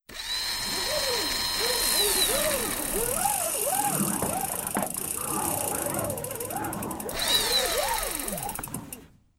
retract.wav